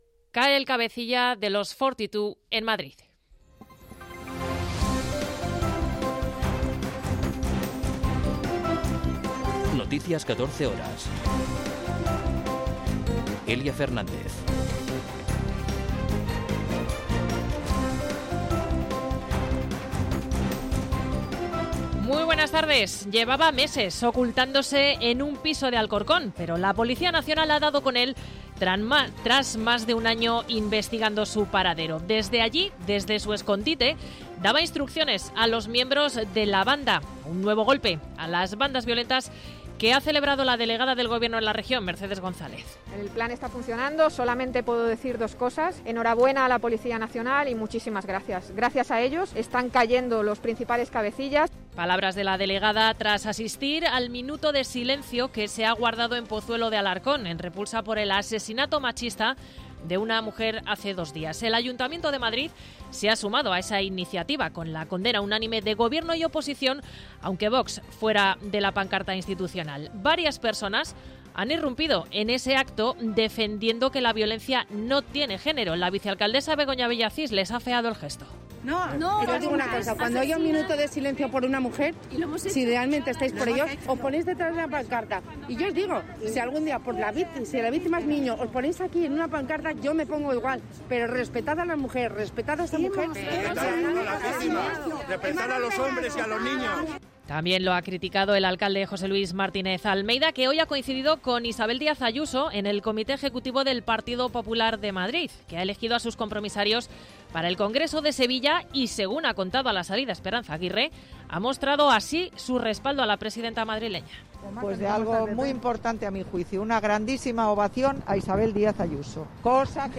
Noticias 14 horas 04.03.2022